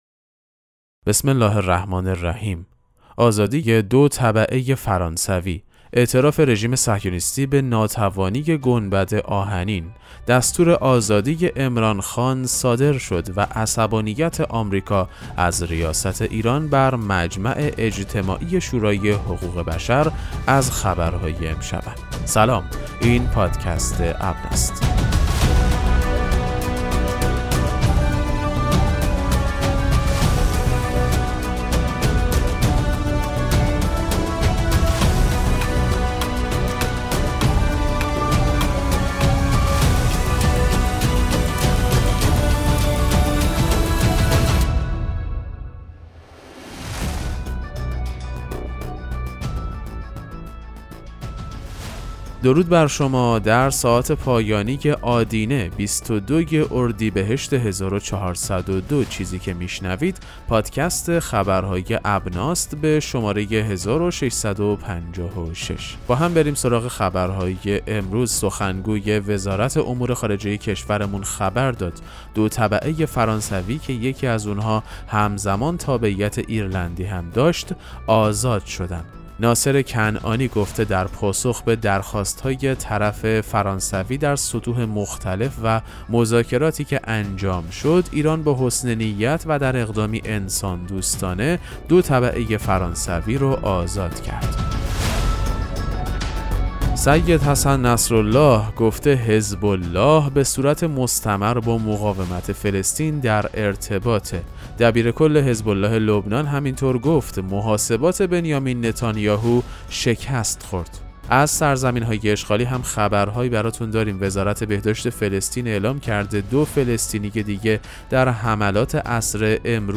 پادکست مهم‌ترین اخبار ابنا فارسی ــ 22 اردیبهشت 1402